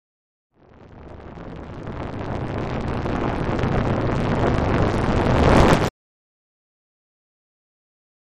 Liquid Hit Slow Liquid Rip Reverse